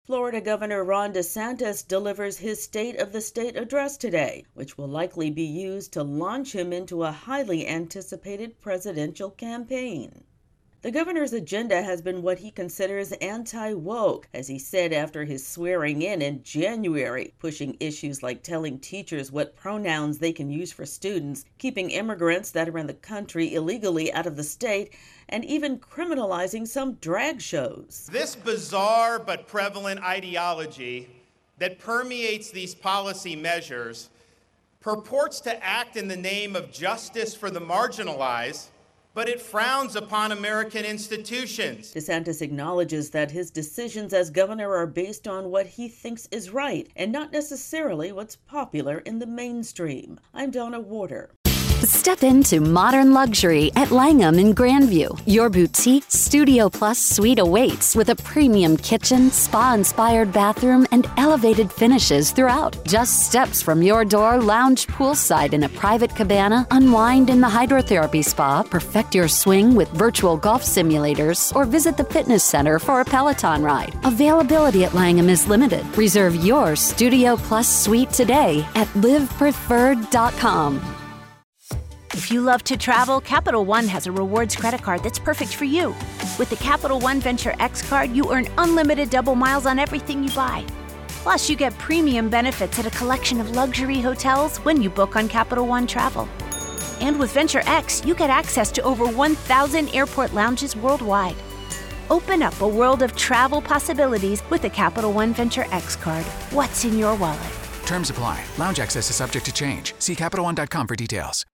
reports ahead of Florida Gov. Ron DeSantis' State of the State address.